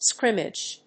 /skrímɪdʒ(米国英語)/